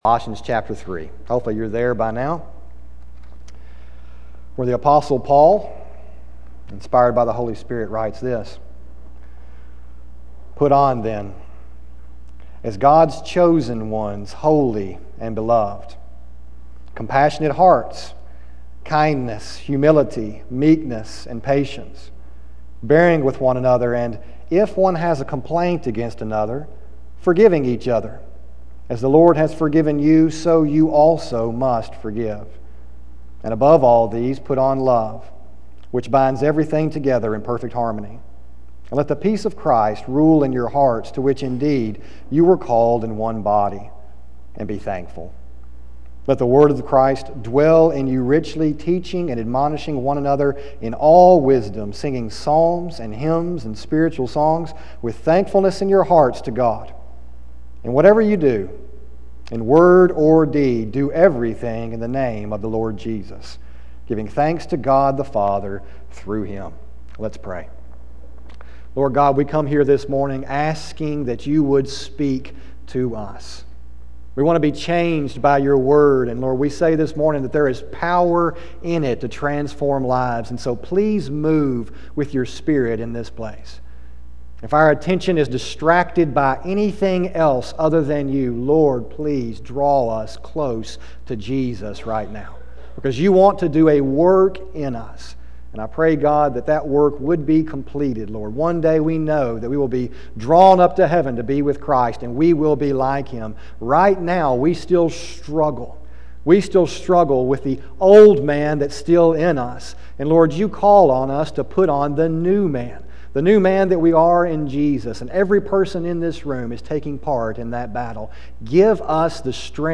sermon102515a.mp3